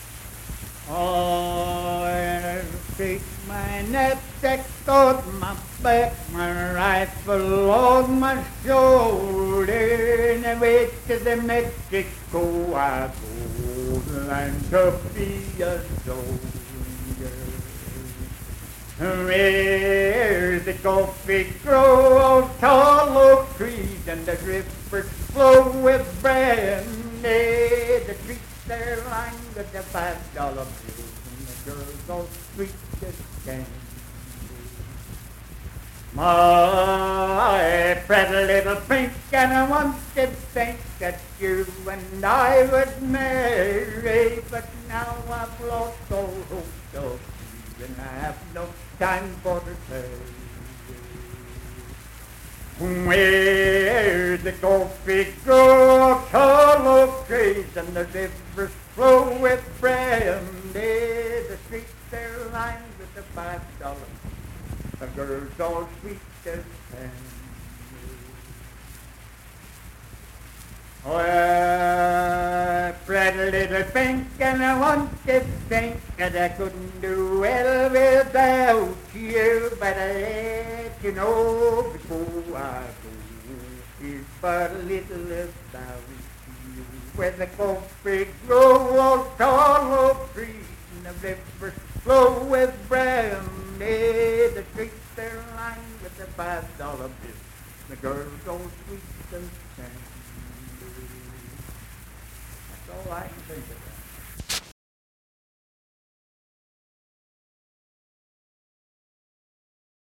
Unaccompanied vocal music performance
Verse-refrain 3(4) & R(4).
Voice (sung)